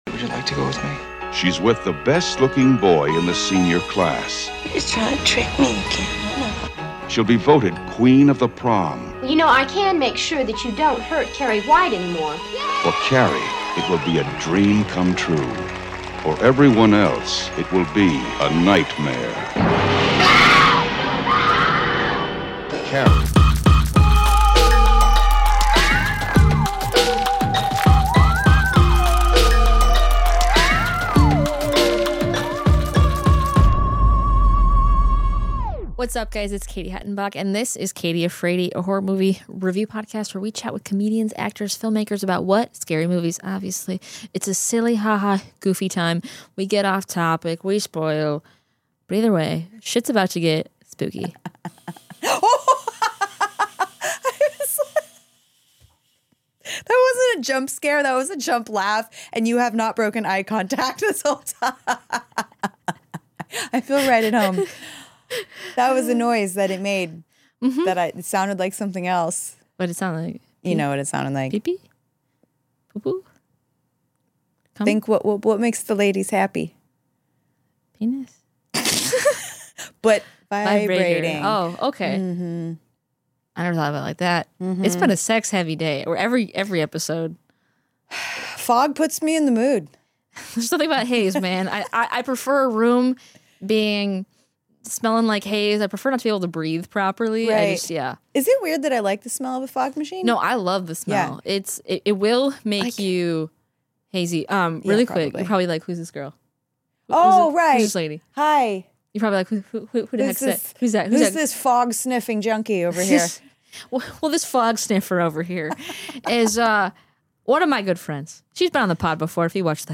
talks with comedians, actors, and filmmakers about horror movies!